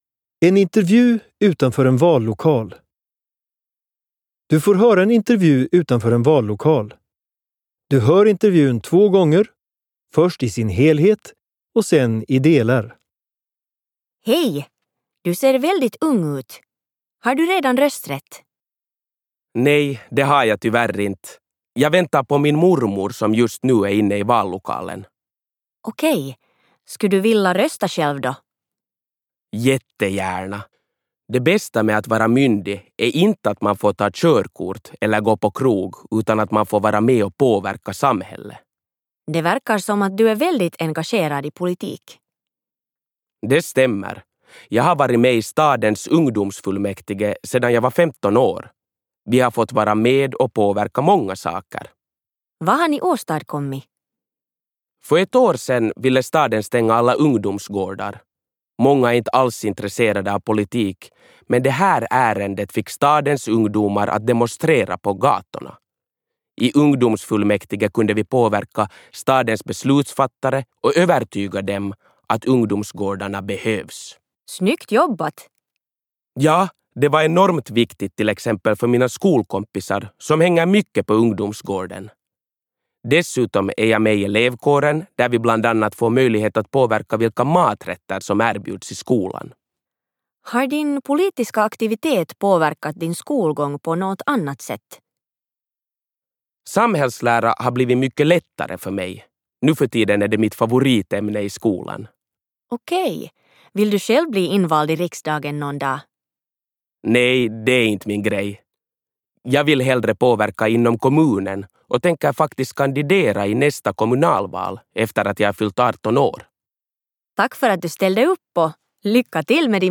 25_Samhalle_Intervju_vallokal_4.mp3